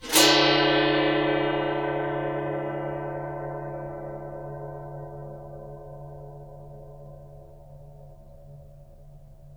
Percussion
gongscrape_mf.wav